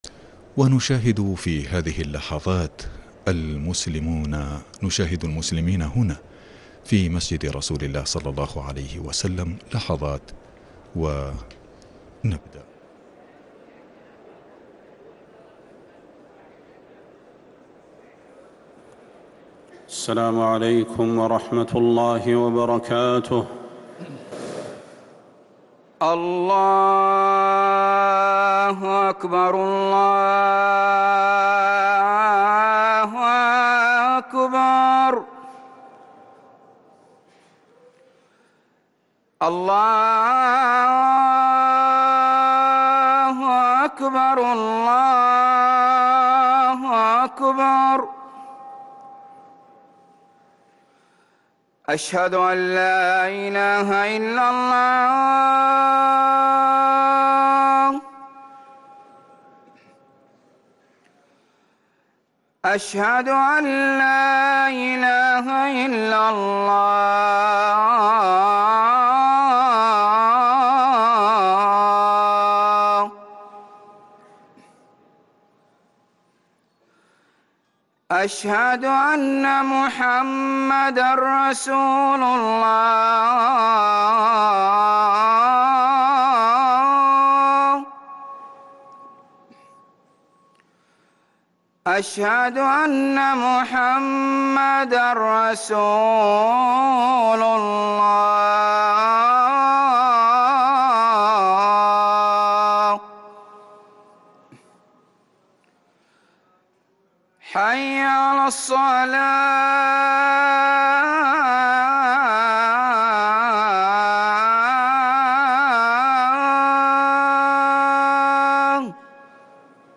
ركن الأذان